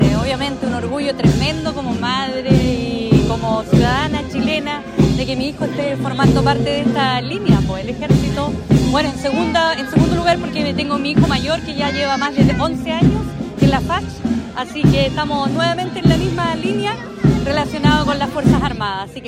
Comprometidos con la patria, 75 soldados del Destacamento de Montaña N°8 “Tucapel” realizaron el juramento a la bandera en un acto conmemorativo desarrollado en la Plaza Aníbal Pinto, rindiendo un solemne homenaje al pabellón nacional, en el marco de la conmemoración del Combate de la Concepción y el Juramento a la Bandera realizado por suboficiales, soldados de tropa profesional y soldados conscriptos del Destacamento.